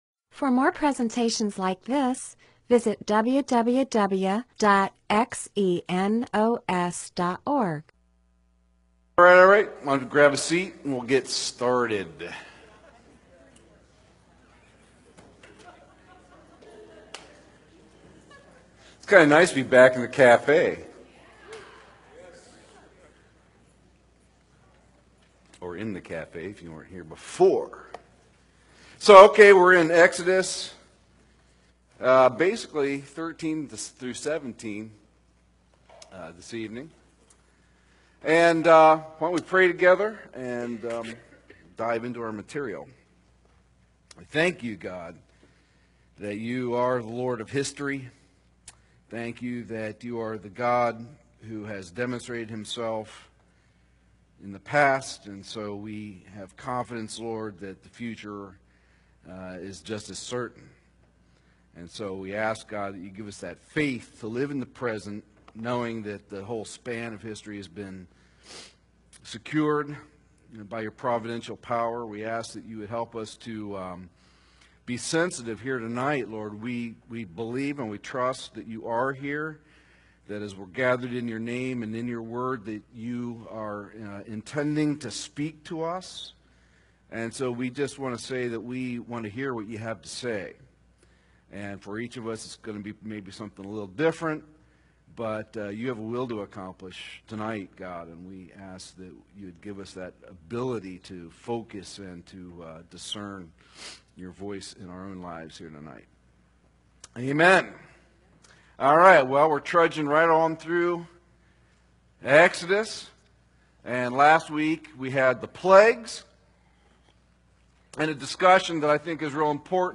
Bible teaching (presentation, sermon) on Exodus 13:17-15:21, Title: Parting the Red Sea-- Marking the Moment, Date: 01/01/2008, Teacher